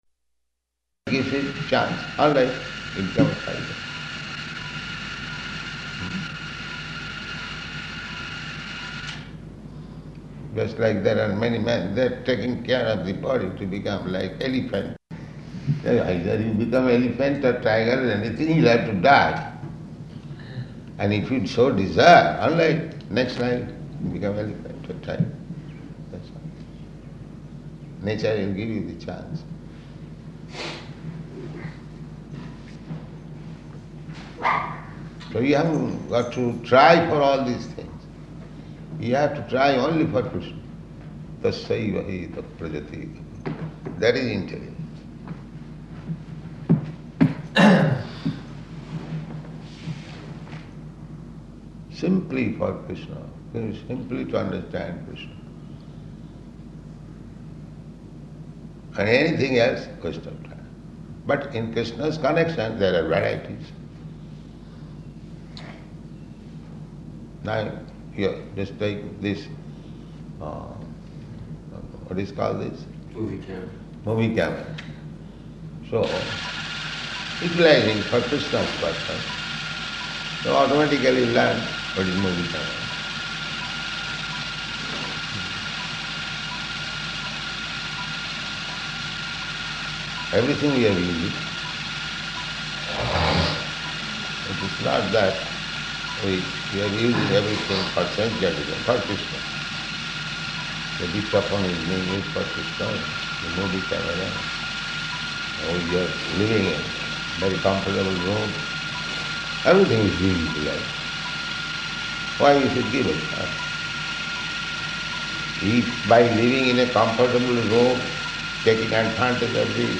Room Conversation
Room Conversation --:-- --:-- Type: Conversation Dated: July 9th 1973 Location: London Audio file: 730709R2.LON.mp3 [Movie camera noise] Prabhupāda: Give him chance, alright.